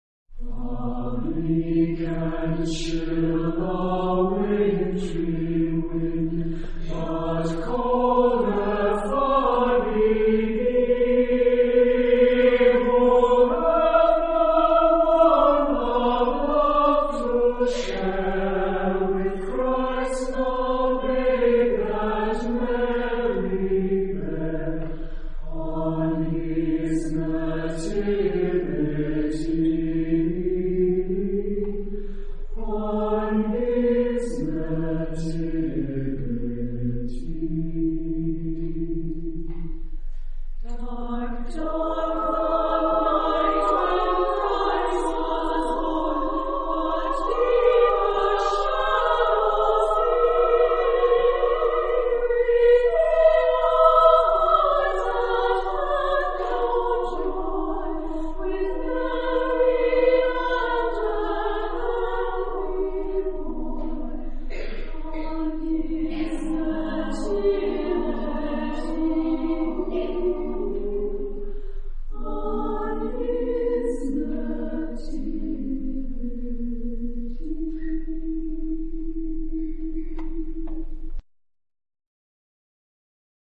SATB OR SSATB (4 voices mixed) ; Close score.
Christmas carol.
Tonality: E major